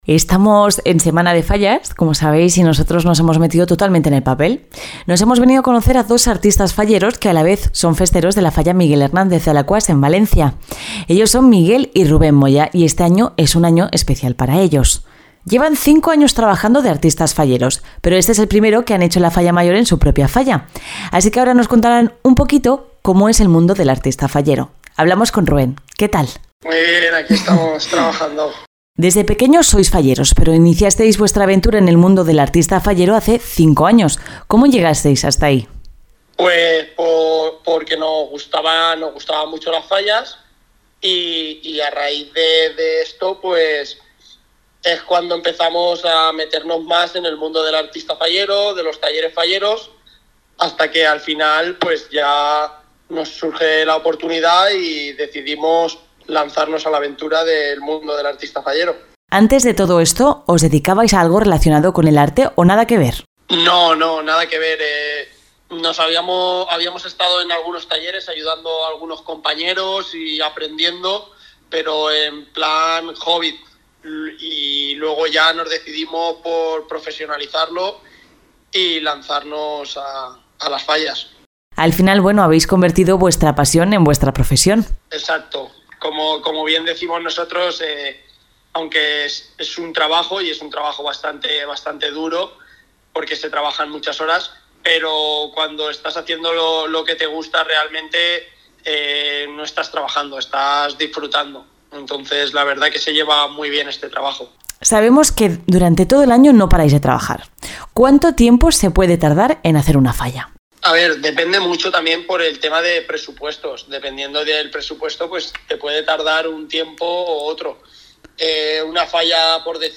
Os dejamos aquí la entrevista:
OK-ENTREVISTA-ARTISTAS-FALLEROS-1.mp3